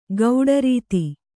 ♪ gauḍa rīti